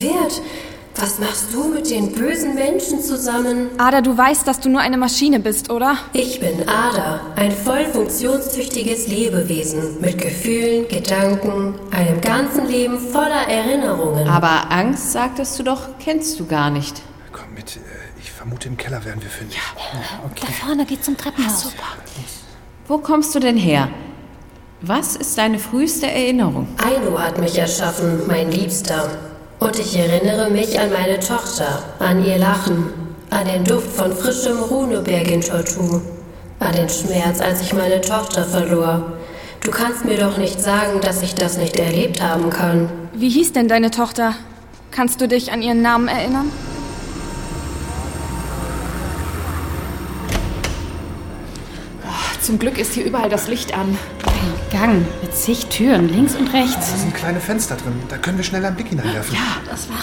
(Hörbuch/Hörspiel - CD)
Hörspiele